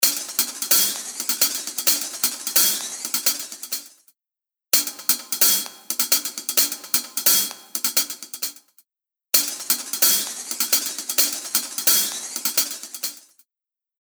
H910 Harmonizer | Hi-Hats | Preset: Drum Gallop
H910-Harmonizer-Eventide-HiHats-Drum-Gallop.mp3